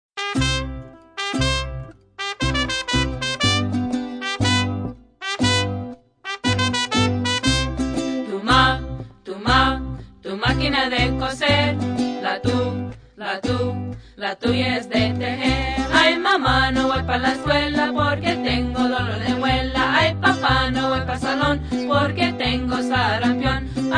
and instrumental parts.
Part Two starts with the change in the music.
Spanish Song Lyrics